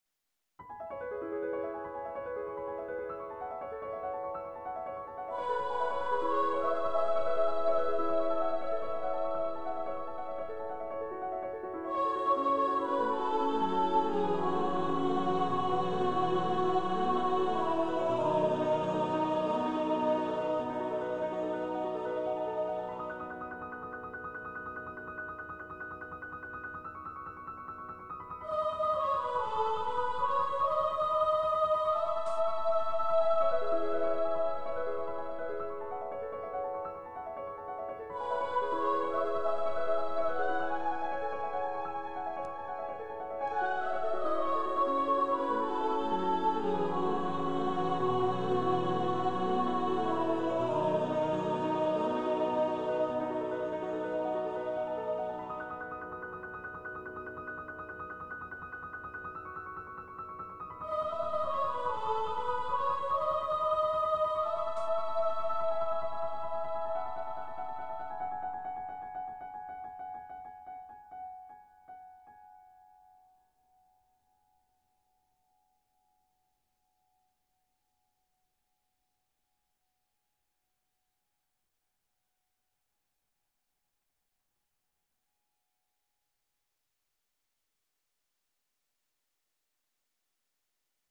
Voice, Piano
Composer's Demo